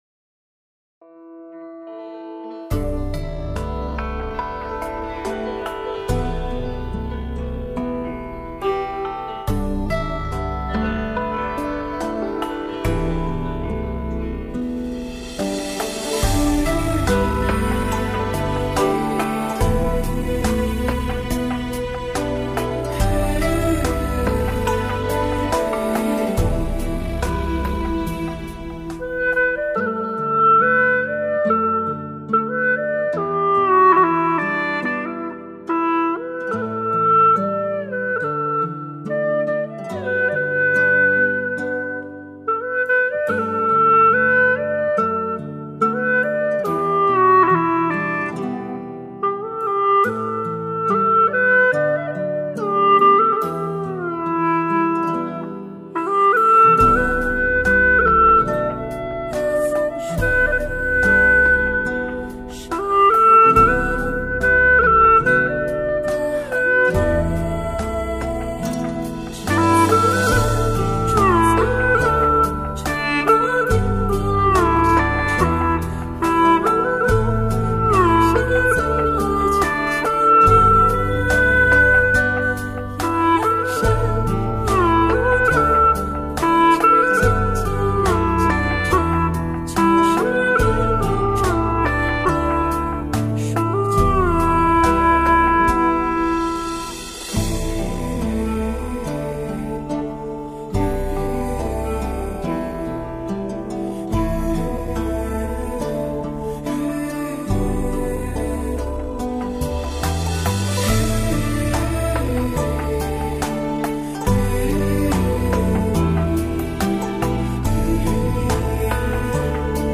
调式 : G 曲类 : 古风